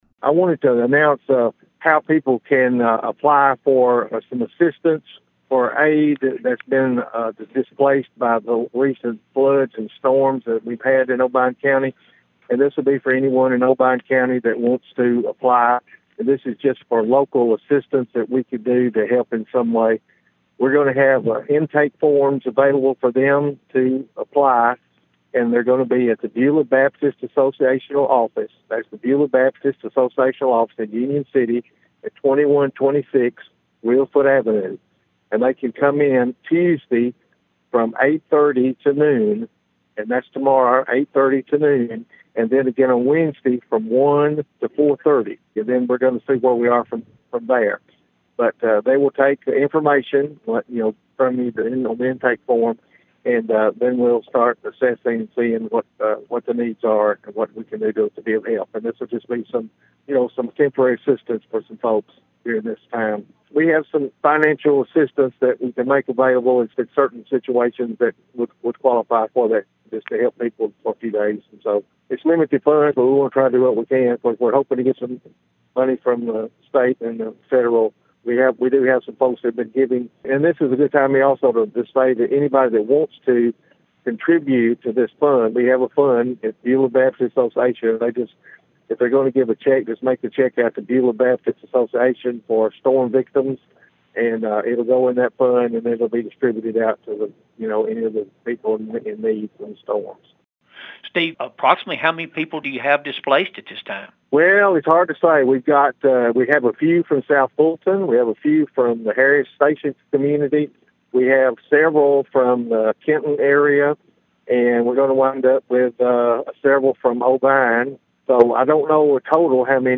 Mayor Carr told Thunderbolt News about the opportunity for residents to apply for assistance on Tuesday and Wednesday at Beulah Baptist Association Office in Union City.(AUDIO)